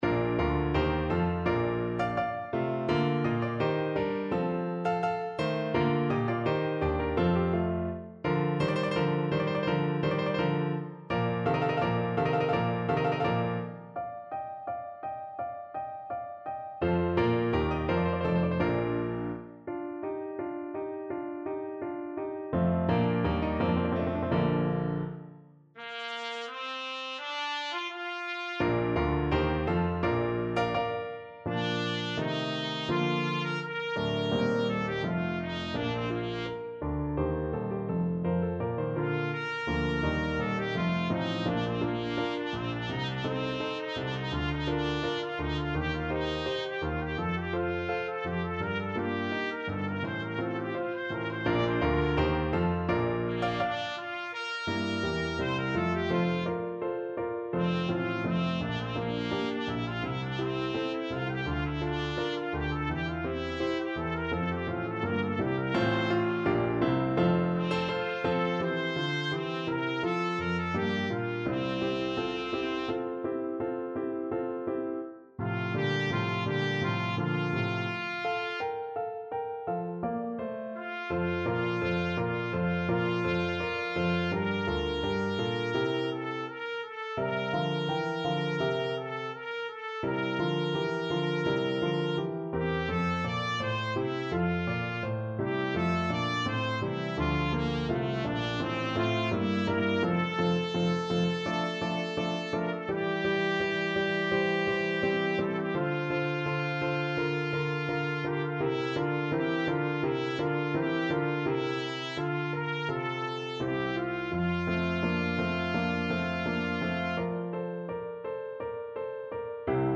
Classical Handel, George Frideric Ev'ry valley shall be exalted from Messiah Trumpet version
Trumpet
Bb major (Sounding Pitch) C major (Trumpet in Bb) (View more Bb major Music for Trumpet )
Andante (=c.84)
4/4 (View more 4/4 Music)
Classical (View more Classical Trumpet Music)